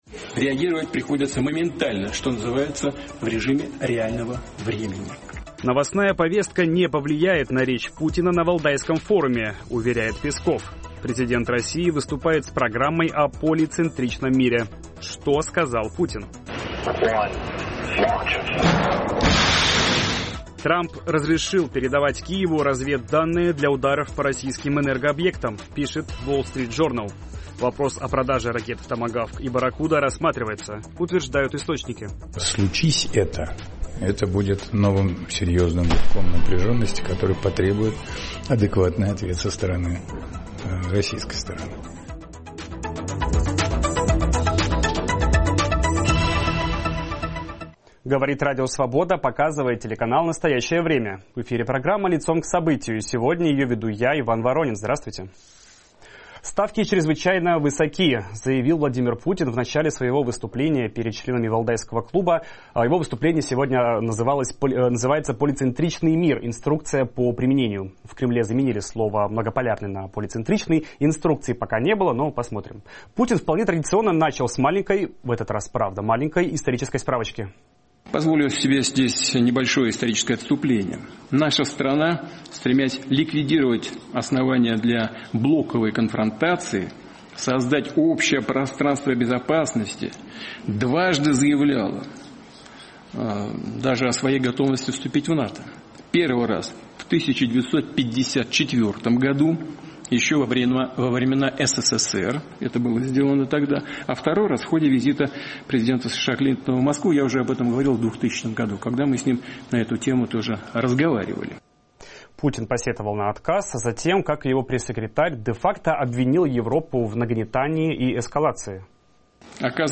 обсуждаем в программе "Лицом к событию" с политологом